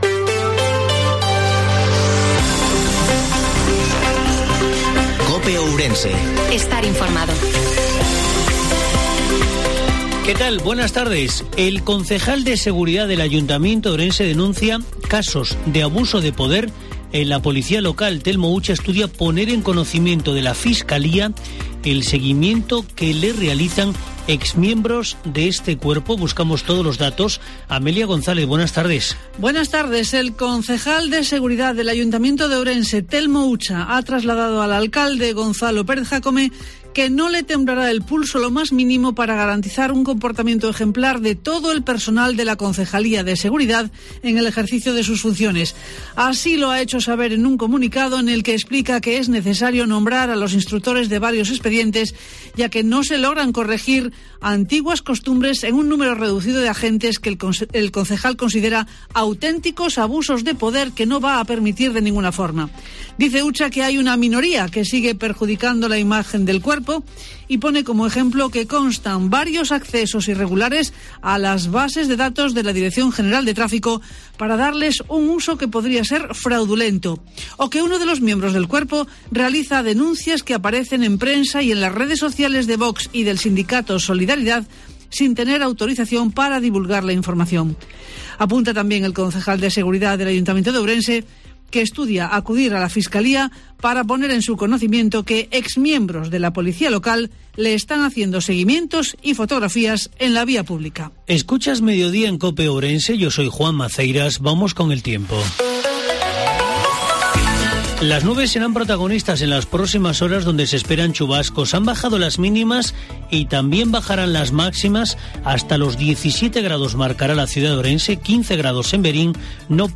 INFORMATIVO MEDIODIA COPE OURENSE 30/03/2022